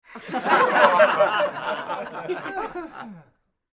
Laugh Track - Reality Bytes